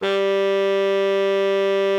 bari_sax_055.wav